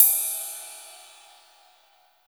39 RIDE CYM.wav